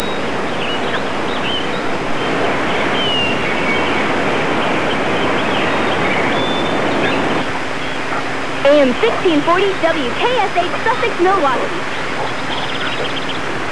These are sound clips of some of my airchecks.
WKSH Radio Disney Legal ID During Stunt (11-14-02)